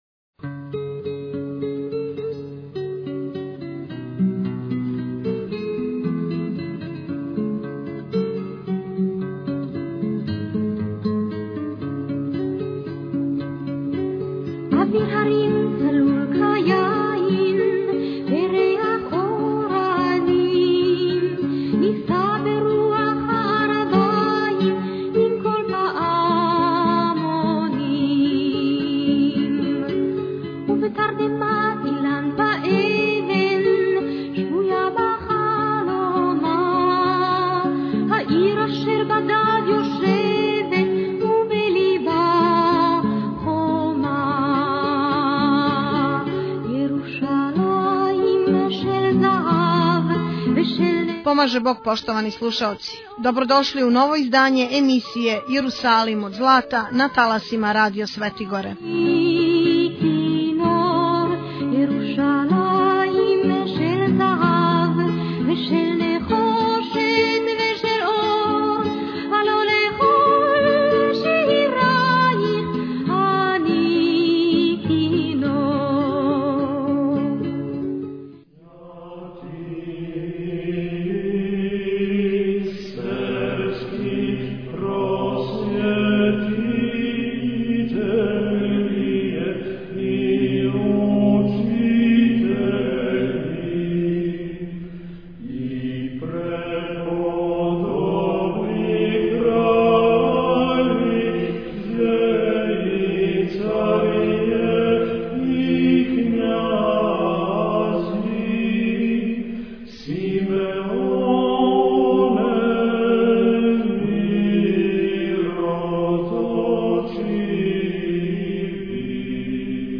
за крај емисије емитујемо разговоре са преживјелим логорашима.